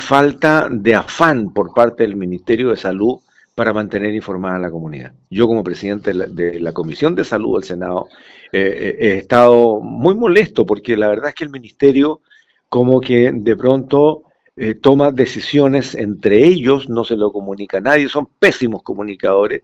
Sin embargo, en conversación con La Radio, el senador Iván Flores criticó el rol del Ministerio de Salud respecto a la entrega de información a los habitantes de La Unión.